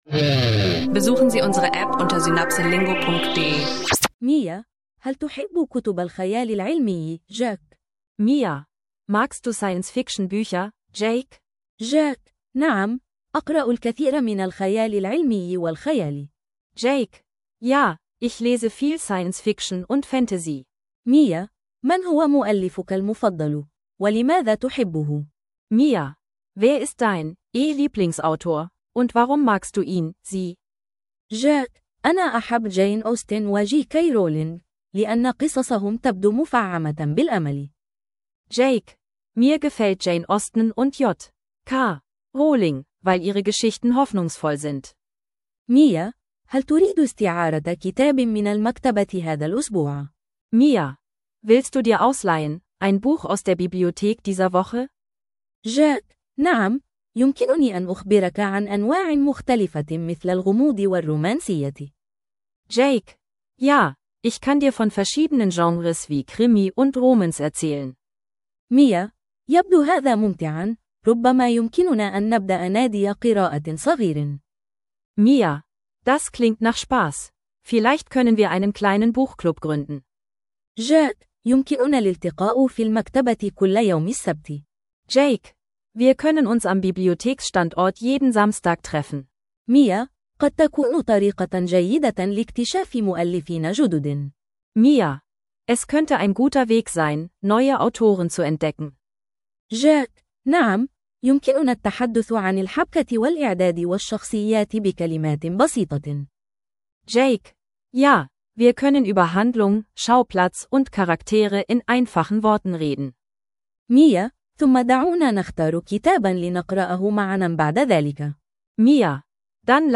Kurzdialoge über Bücher, Autoren und Literarisches – effektives Arabisch lernen mit Podcast-Dialogen